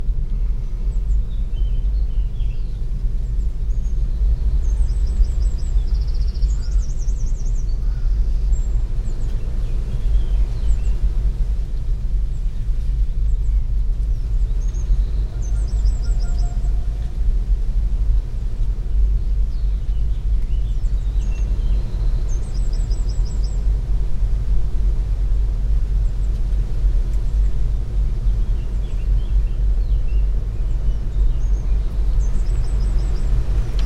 There is also a vibration in the audible range in the proximity of the megalith called the "Baboon" that the silence and in the absence of wind can be heard quite easily into the cavity present in it.
it is a dominant frequency of 26Hz followed by various harmonics up to about 60Hz.
listen here - use again Hi-Fi headphones), given its characteristics it is conceivable to be a movement attributable to an underground stream that visitors to the site in ancient times may have perceived as the sound of God voice. We have decided to call it "the Voice of Argimusco".